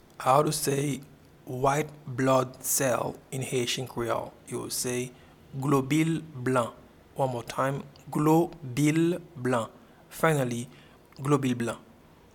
Pronunciation and Transcript:
White-blood-cell-in-Haitian-Creole-Globil-blan.mp3